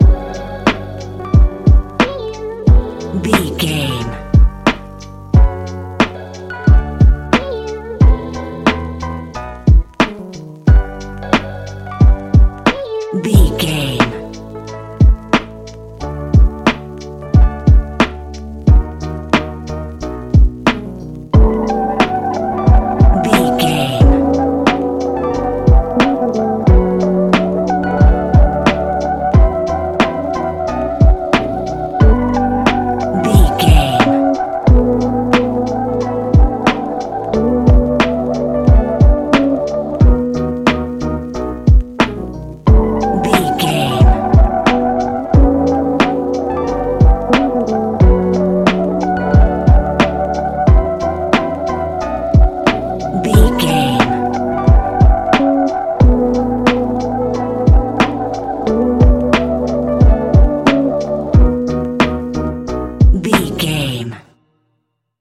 Ionian/Major
D♭
Lounge
sparse
chilled electronica
ambient